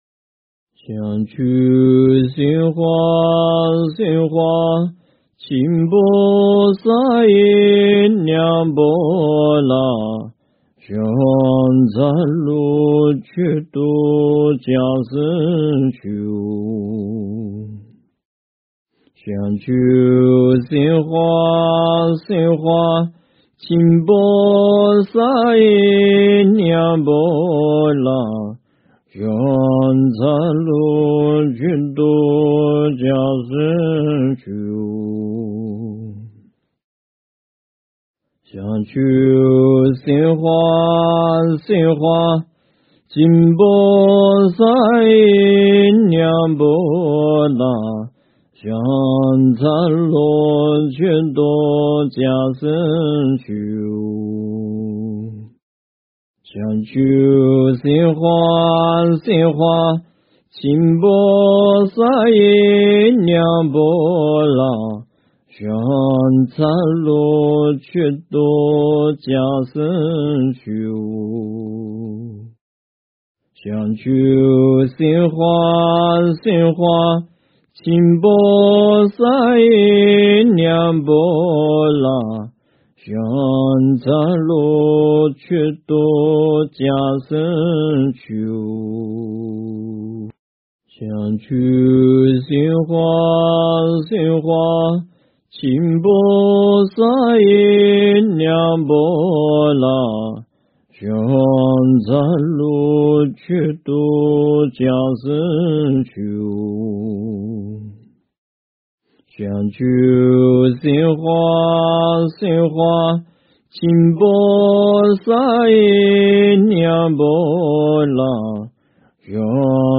经忏
佛音 经忏 佛教音乐 返回列表 上一篇： 语加持--希阿博荣堪布 下一篇： 普庵咒（唱诵）--文殊院 相关文章 献供赞（唱诵）--文殊院 献供赞（唱诵）--文殊院...